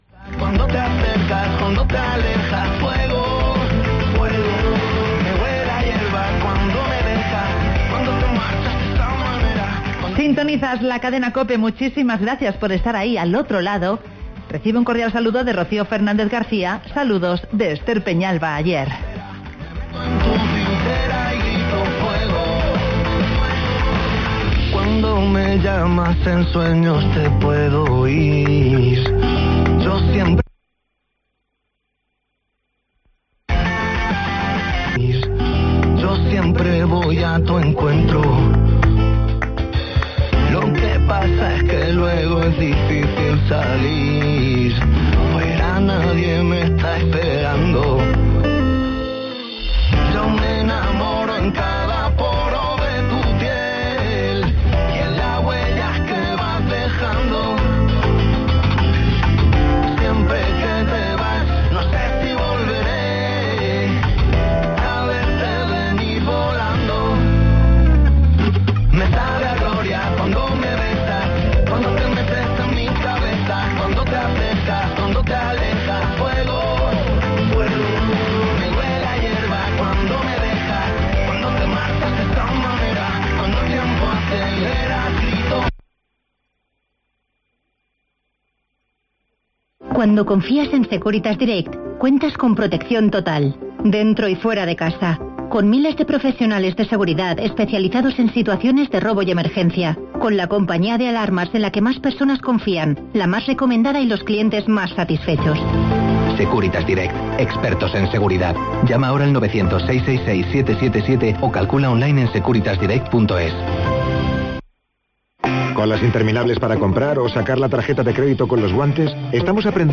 El Banco de Alimentos del Sil estrena nuevo formato para la gran recogida anual (Entrevista